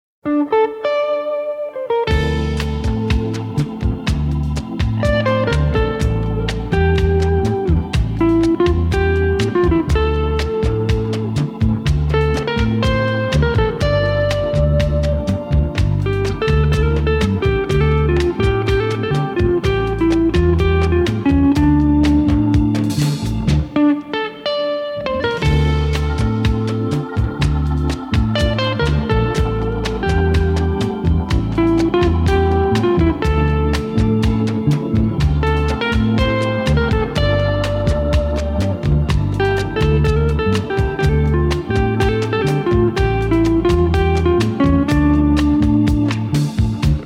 гитара
dance
без слов
красивая мелодия
инструментальные